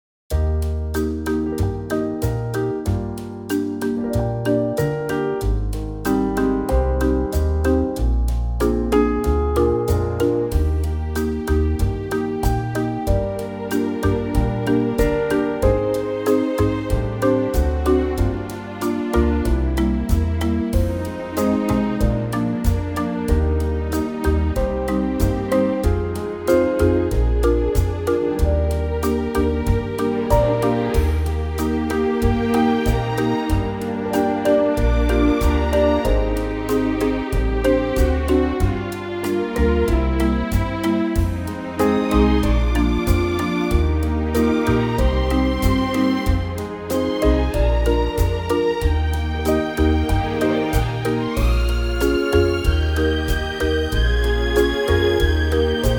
key - Eb - vocal range - Eb to F
-Unique Backing Track Downloads